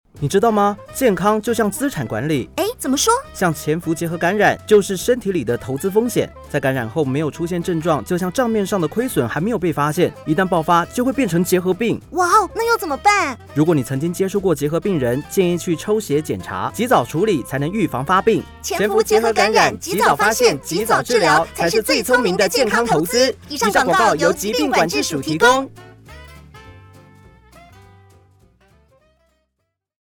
潛伏結核感染30秒廣播-投資健康篇_國語.mp3